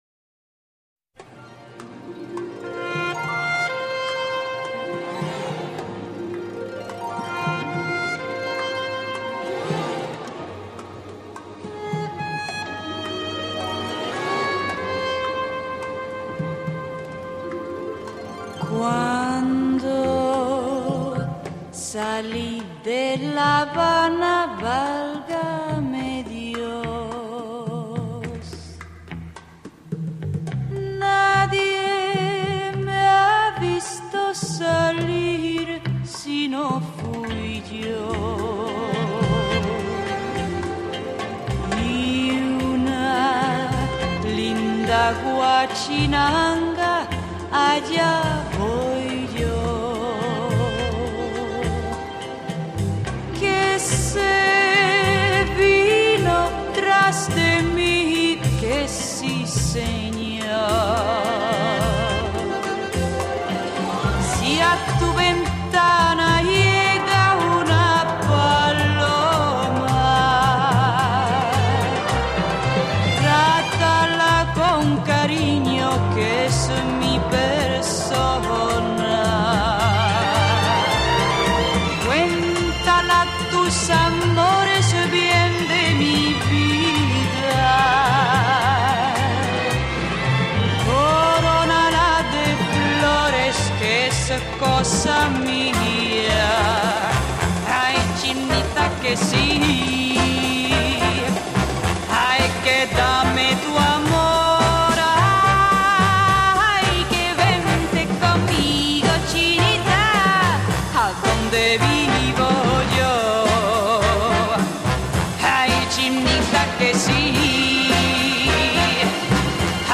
由于是早年录音，为保持原音，故以 WAV 单曲格式 陆续登出，与网友共享